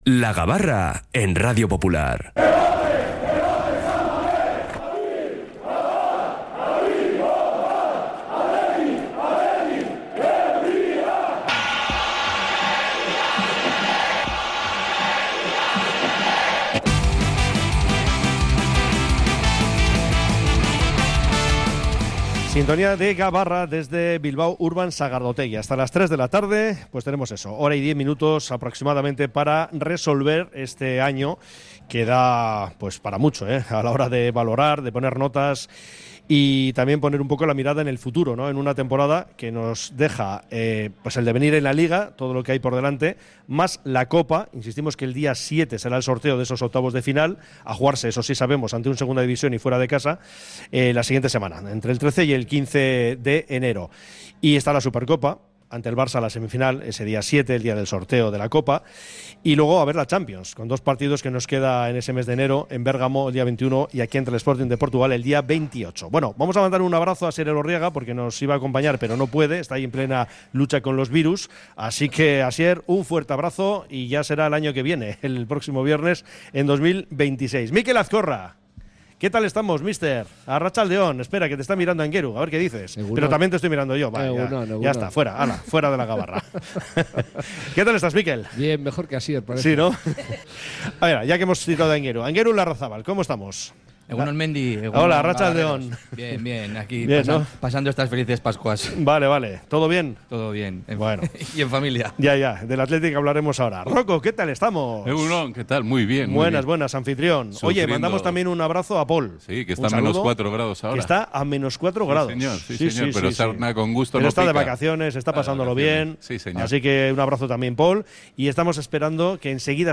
Desde Bilbao Urban Sagardotegi hemos analizado, como cada viernes, toda la actualidad rojiblanca